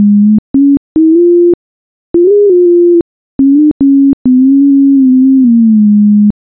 You can play the singing pitch of the first phrase, and save it to an audio file: